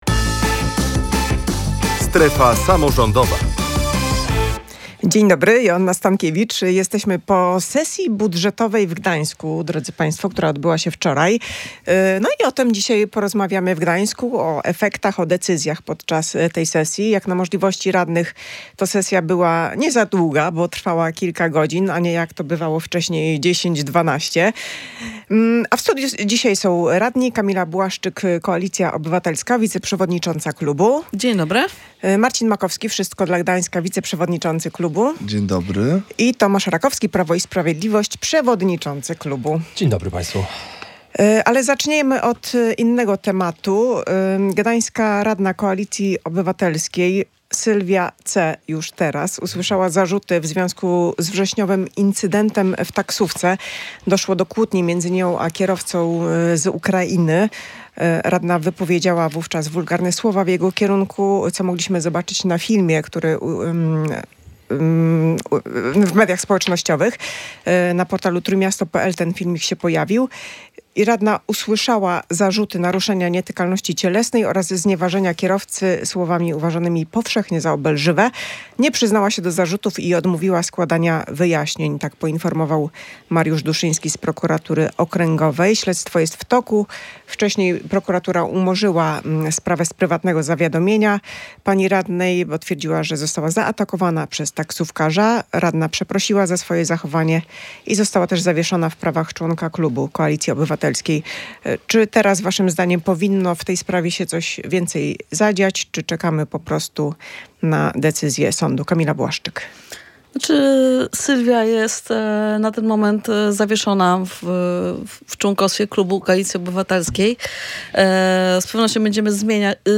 Gorąca dyskusja w „Strefie Samorządowej”